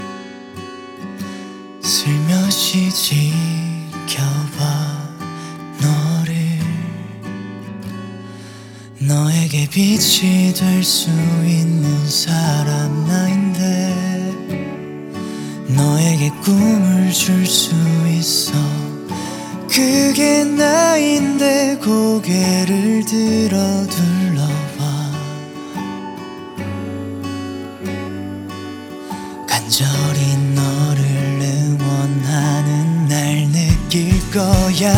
Скачать припев
2025-05-03 Жанр: Соундтрэки Длительность